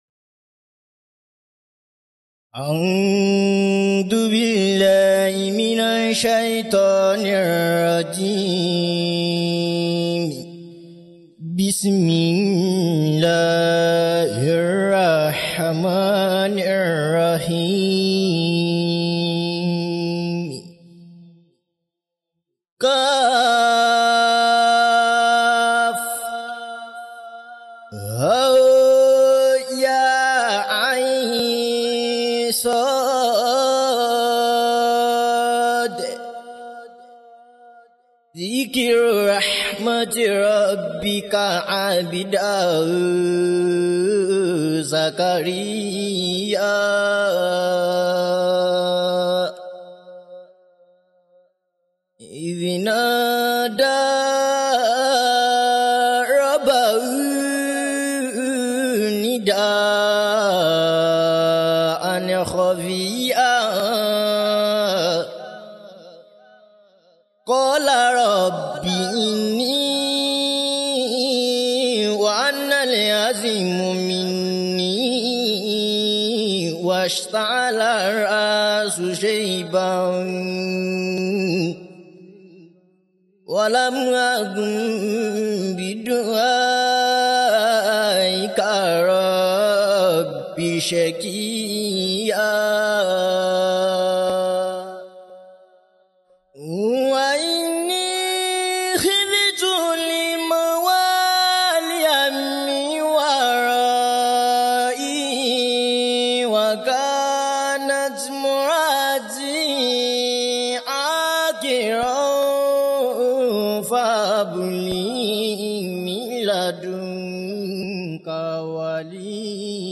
Al-Quran Recitation – Lyssna här – Podtail